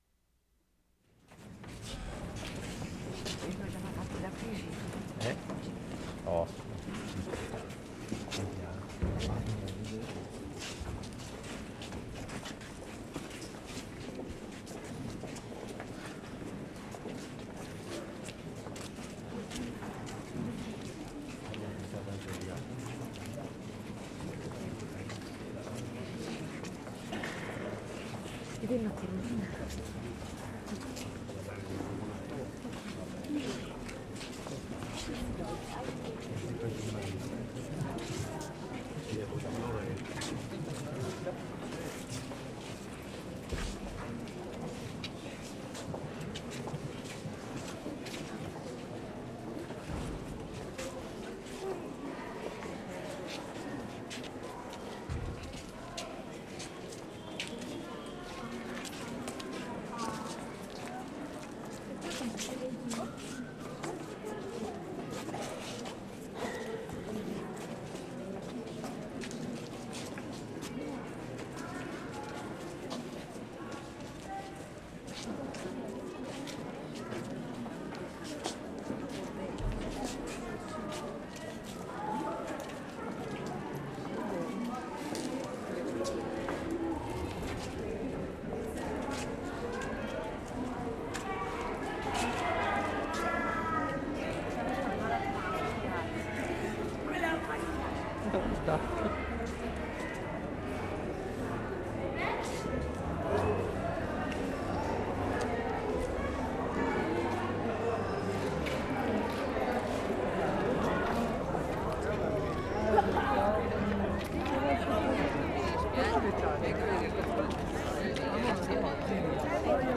Cembra, Italy March 29/75
mark * congregation leaving church. [1:50]
7. Lots of talking and shuffling as people leave church. Good change in ambience from inside to outside. All singing in the service is unaccompanied (no organ).